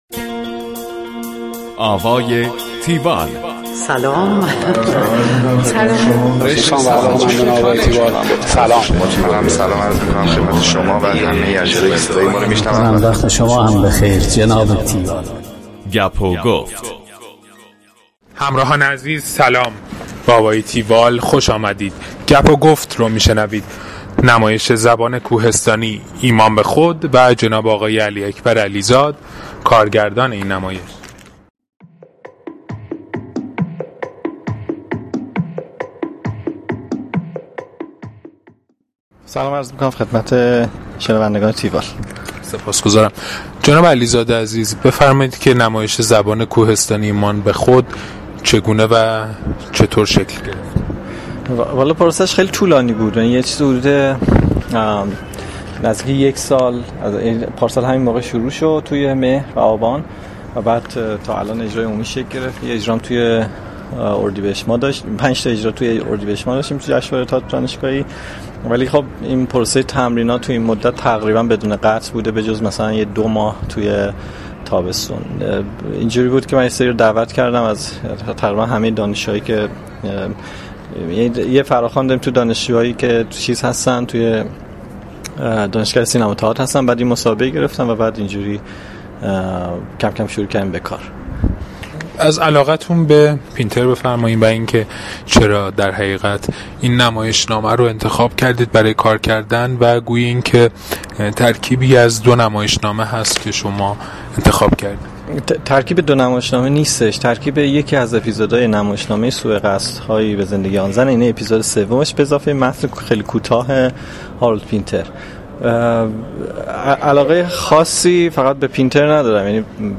گفتگوی تیوال با